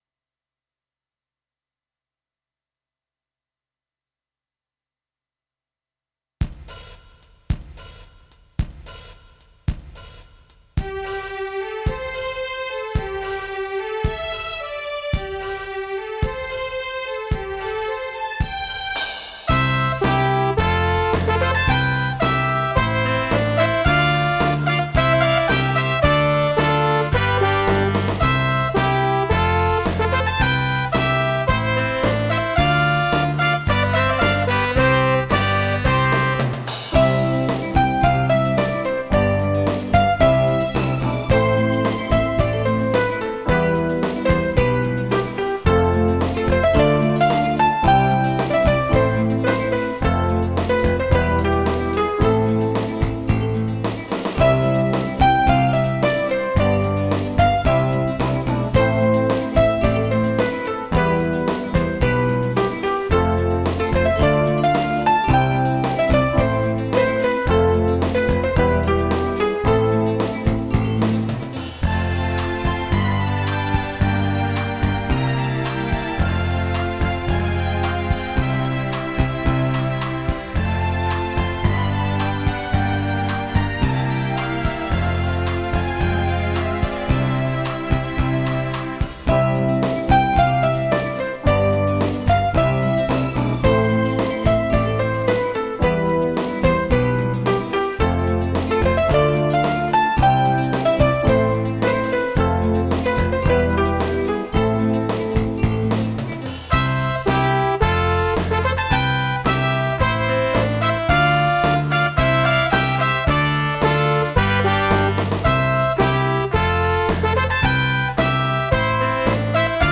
( 5.509Kb )   この曲は、最後の曲というかエンディングテーマみたいなイメージがあります。
この曲のギターのパートはDX-7IIを使ったと思います。
鍵盤楽器でギターっぽく弾くのに苦労したような...記憶があります。
使用機材 上から　DX7II、U-20、SY-77